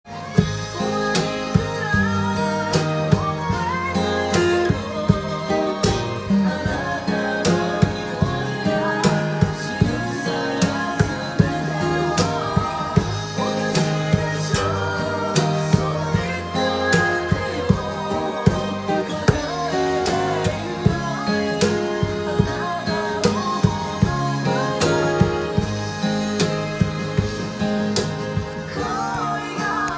アコースティックギターで譜面通り弾いてみました
１カポをするとHYのkeyでローコードで弾くことができます。
パーム奏法を入れてみました。
パームが入るとかなりパーカッシブな感じになるので